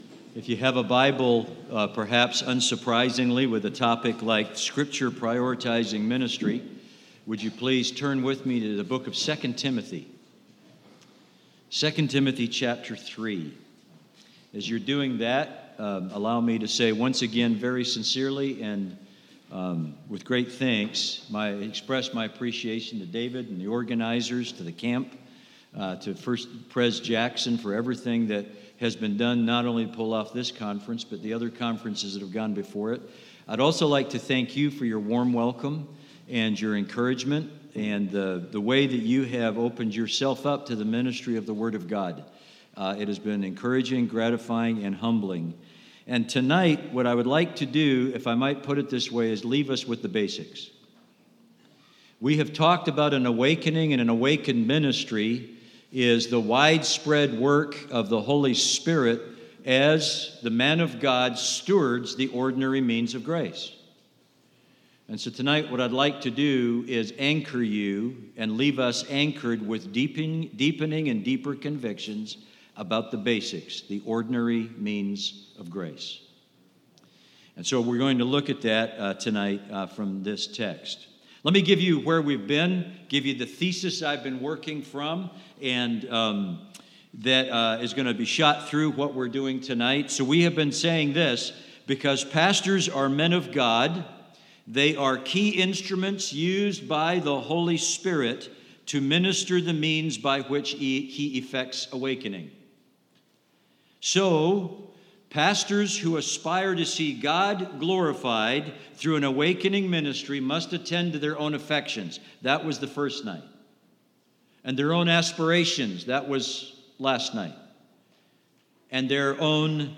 Worship Service 3: An Awakening Ministry is a Scripture-Prioritizing Ministry